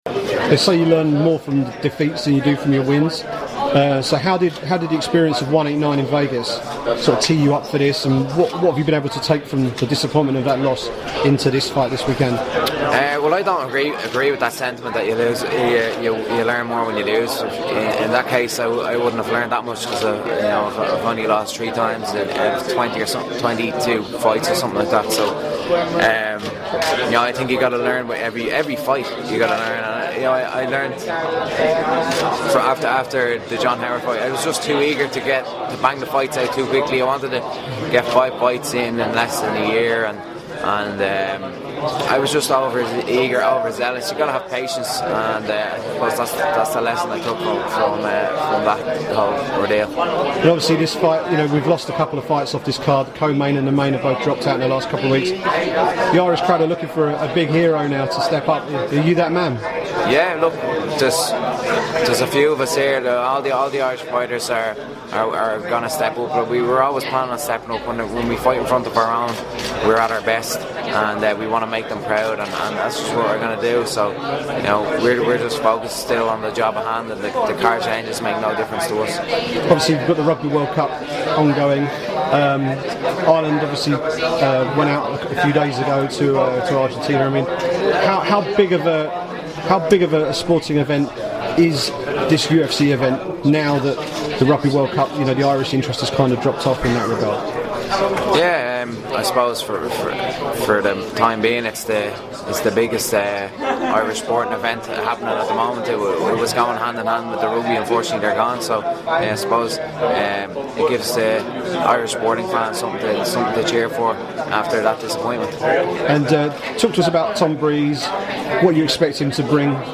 at the UFC Dublin media day